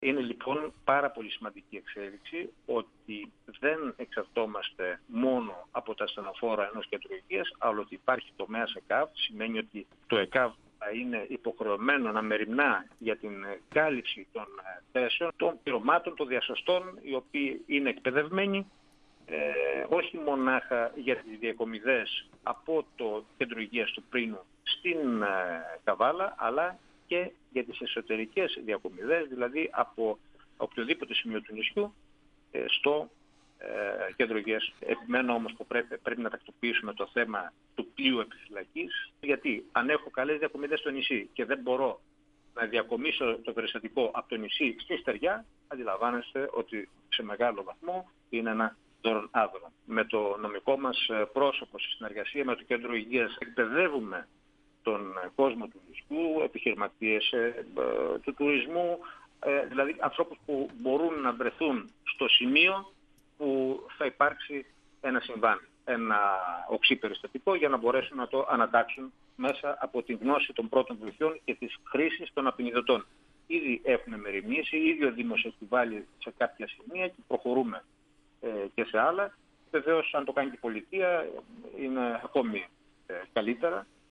Ο δήμαρχος Θάσου Κωνσταντίνος Χατζηεμμανουήλ, στον 102FM του Ρ.Σ.Μ. της ΕΡΤ3
Συνέντευξη